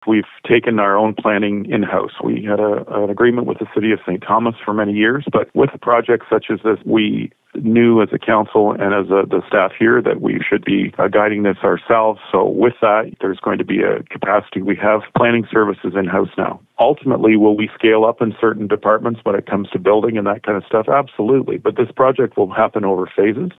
Due to the scope of the development over the coming decades, Sloan advises the municipality will need to hire additional staff.